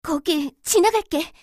slayer_f_voc_skill_flashhunter.mp3